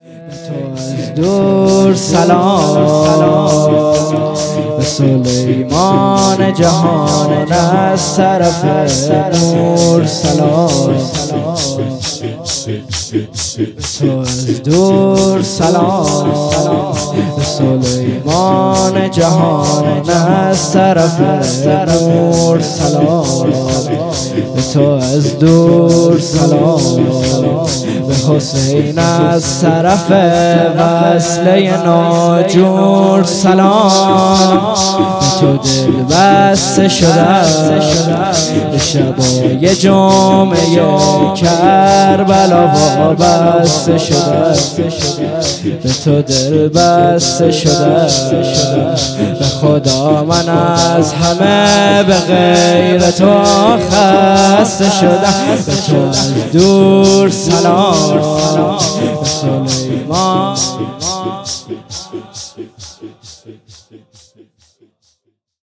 شور 3 شب هشتم محرم 98